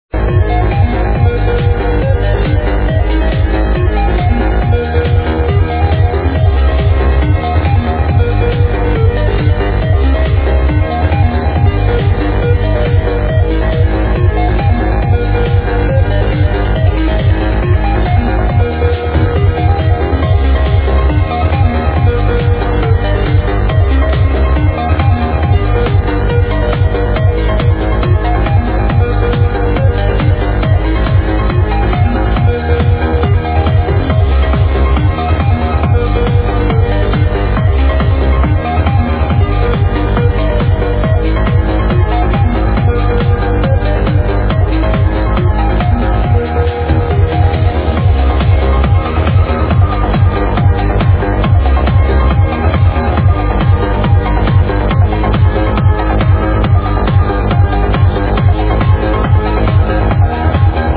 Instrumental Mix.